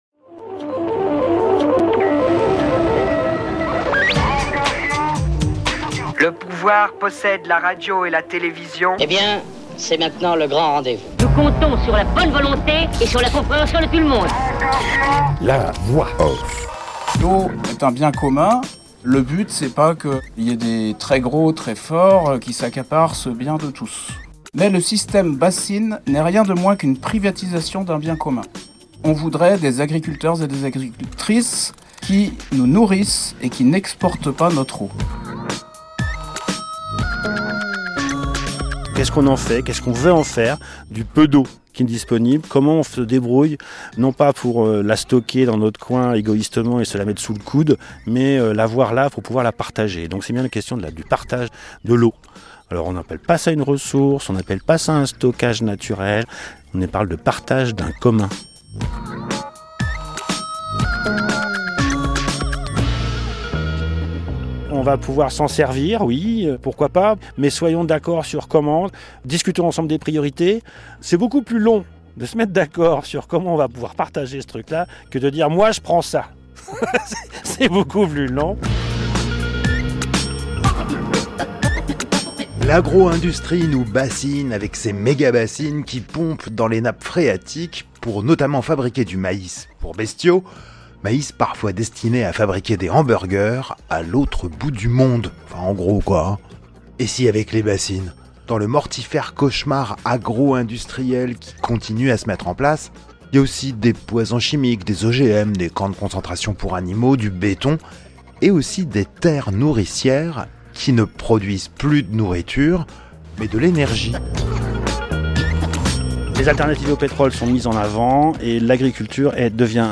Comme tous les ans, passage par le festival Aucard de Tours organis� par Radio B�ton.